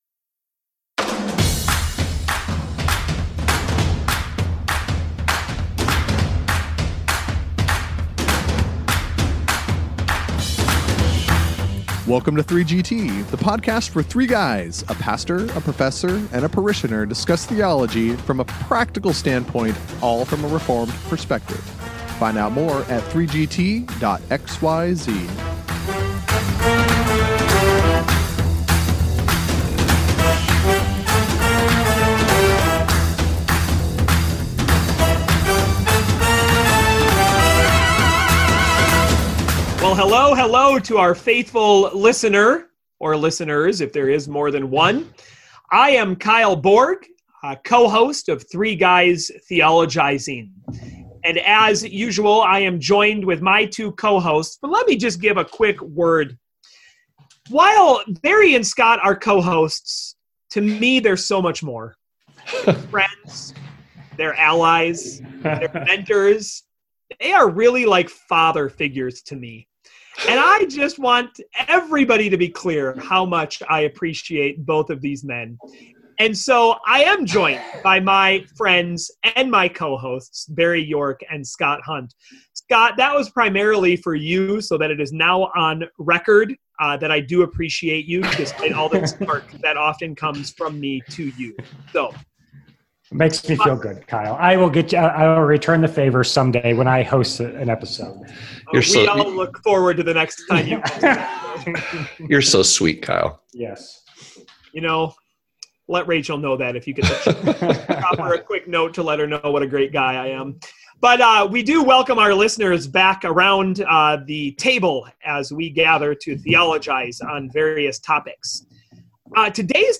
Tune into this lively episode and hear the 3GTers mix it up!